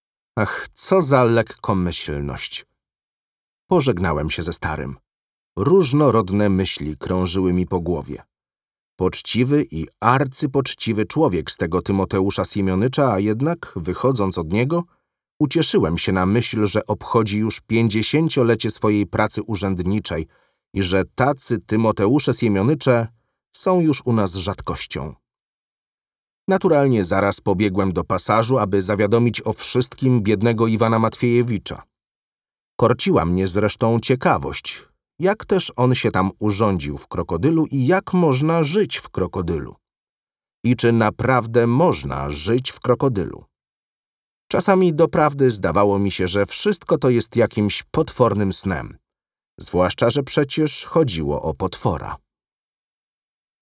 DEMO AUDIOBOOK 3: